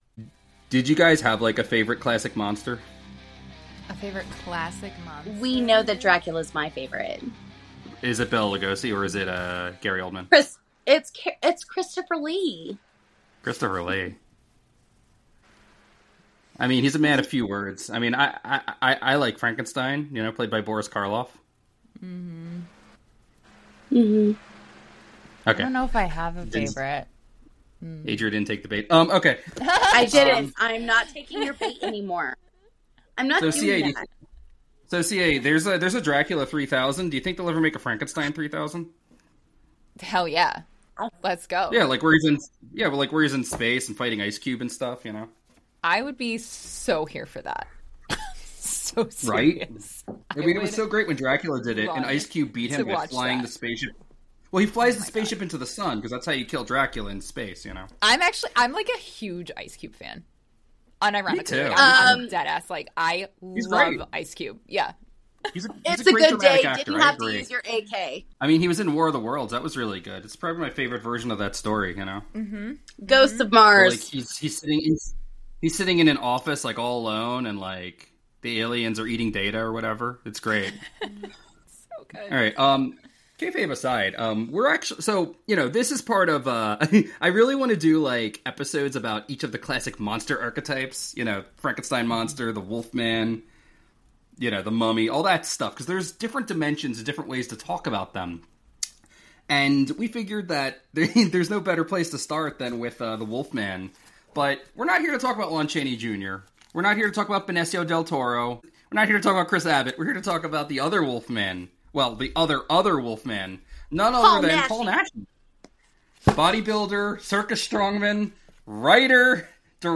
With a blend of humor, nostalgia, and insightful commentary, they unravel the cultural impact and production tales behind these spine-chilling masterpieces.
Tune in for a unique blend of horror history and lively discussion!